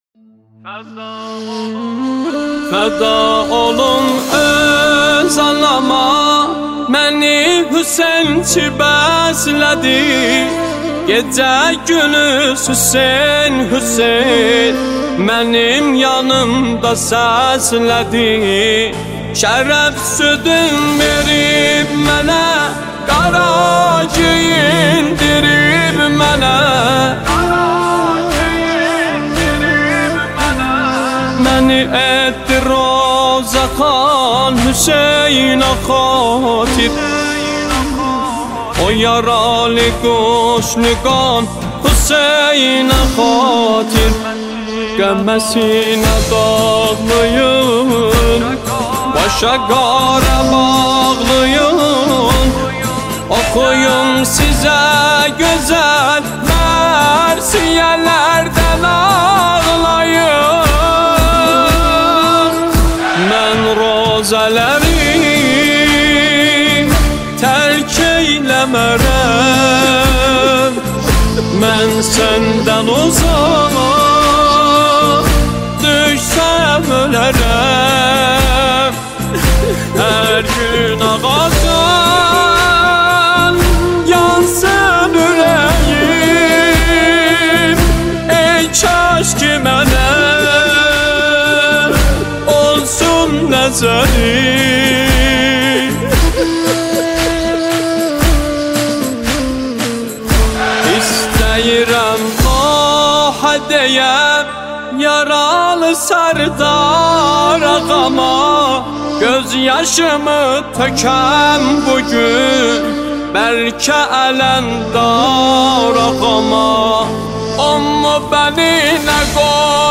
گلچین نماهنگ دلنشین ترکی ویژه ماه محرم